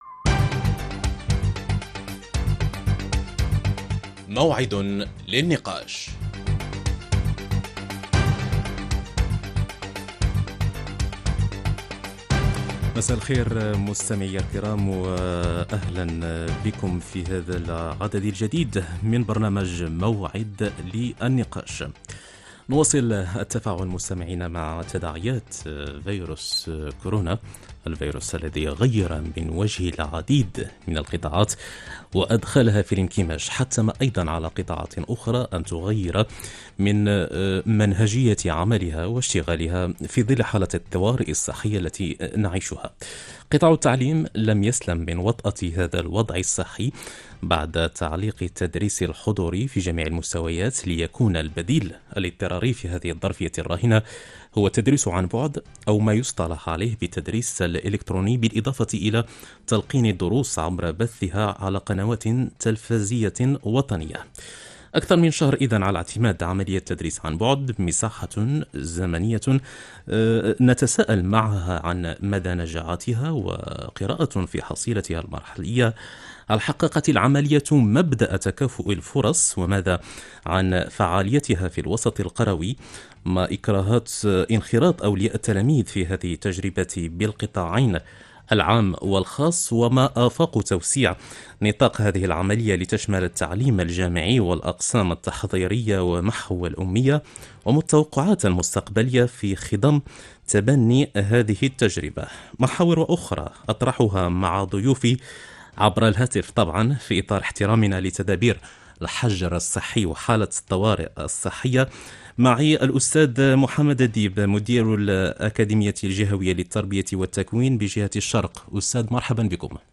Sur les ondes d’Al Idaa Al Wataniya, une émission de débat lui est consacrée et réunit des experts autour de sujets d’actualité et de problématiques liées au sport. Présidents de club, entraineurs et autres spécialistes répondent présent pour vous éclairer et répondre à vos interrogations.